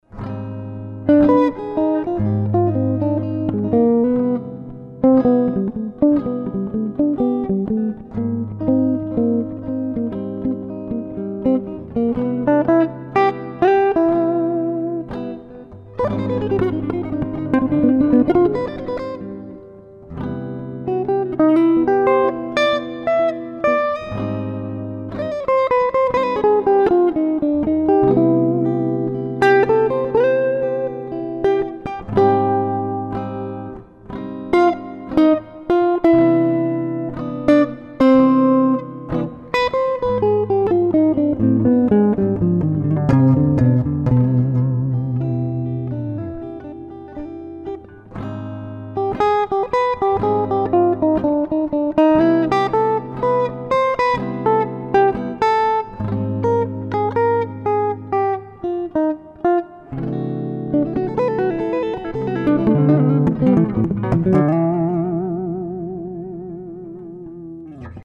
et donc sur le MP3 , je joue Do mineur harmonique sur Lab7M & résoud en majeur ensuite. façon inhabituelle de voir l'altération mais qui n'essaie rien........
Ab7MC7M.mp3